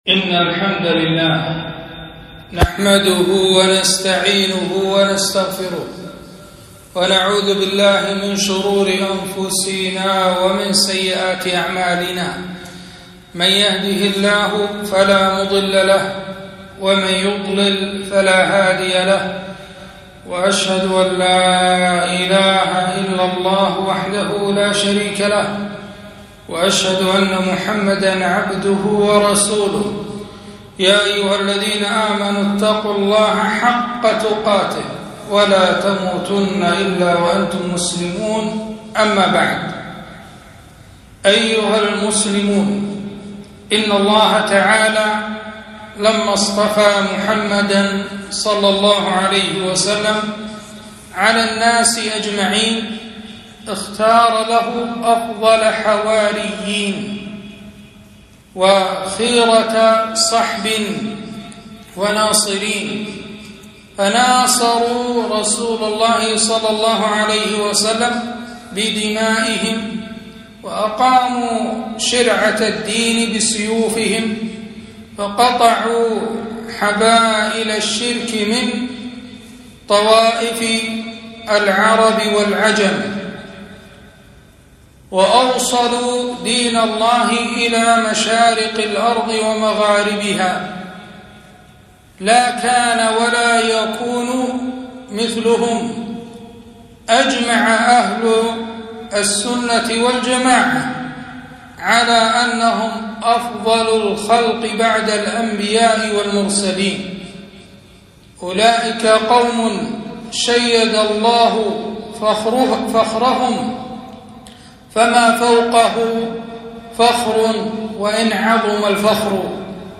خطبة - خير القرون الصحابة الكرام رضي الله عنهم